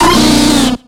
Cri d'Insolourdo dans Pokémon X et Y.